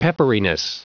Prononciation du mot pepperiness en anglais (fichier audio)
Prononciation du mot : pepperiness